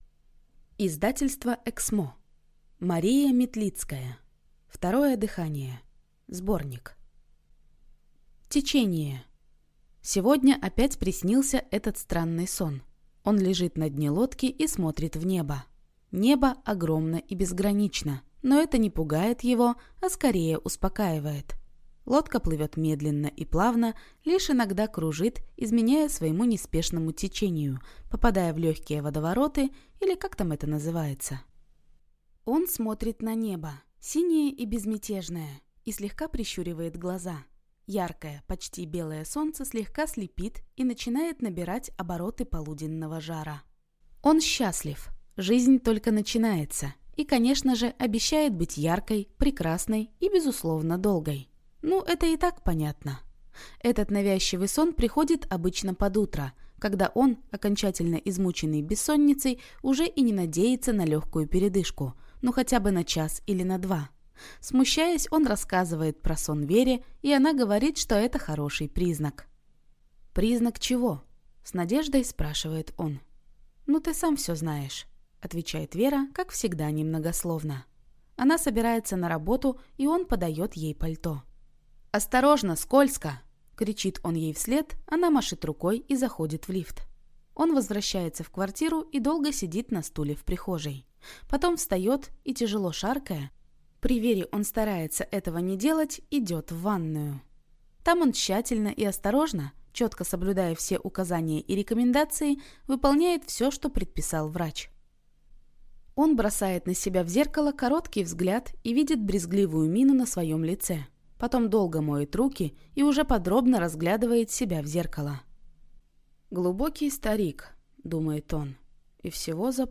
Аудиокнига Второе дыхание (сборник) | Библиотека аудиокниг